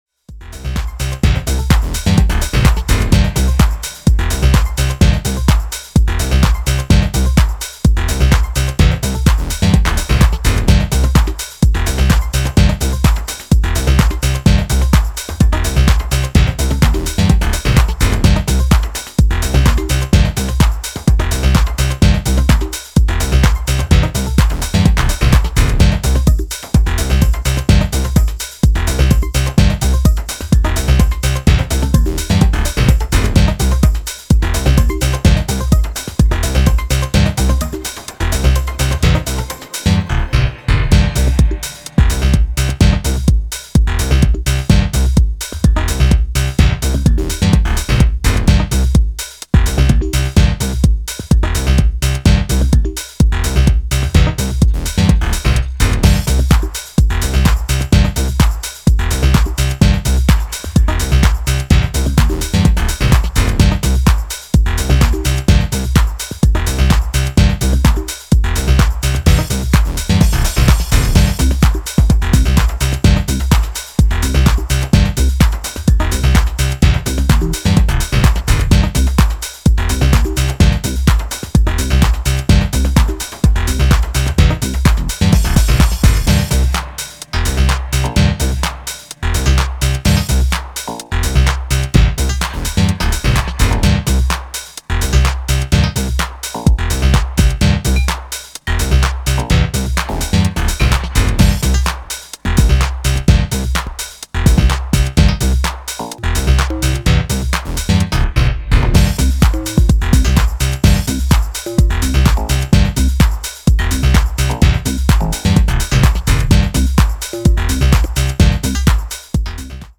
淡々と力強いグルーヴと歪んだ音像のリフが配された